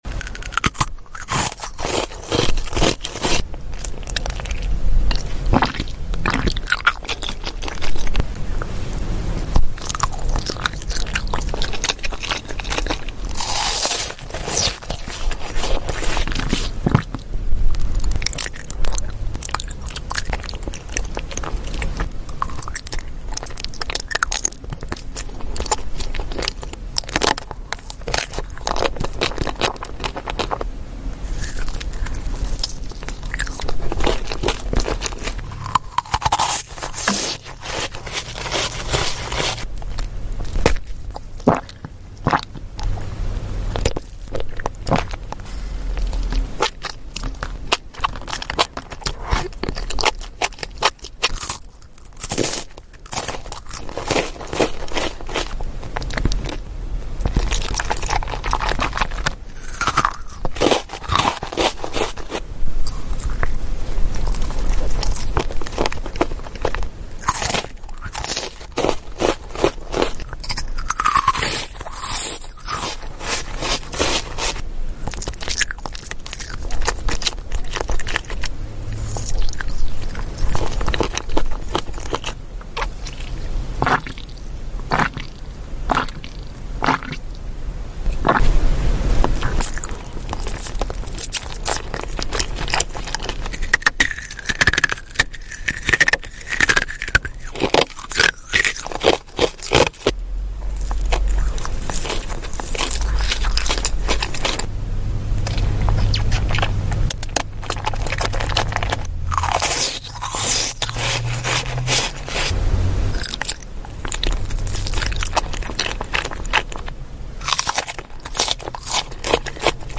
SLEEP SOUND😴speed ver. Asmr sound effects free download